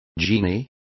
Complete with pronunciation of the translation of genies.